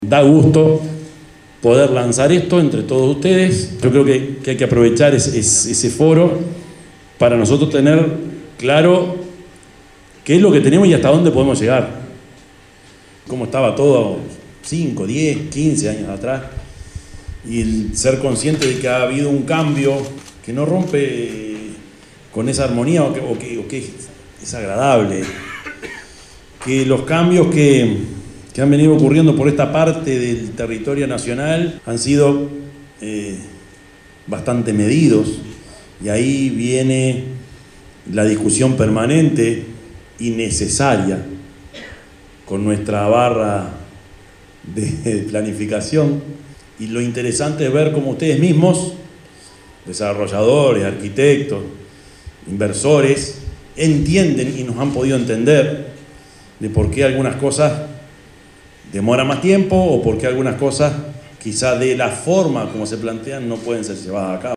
El martes 19 de julio, se realizó el lanzamiento del Foro de Arquitectura y Urbanismo, organizado por la Editorial AyD y promovido por la Intendencia de Canelones a través de la Agencia de Promoción a la Inversión (API).
yamandu_orsi_intendente.mp3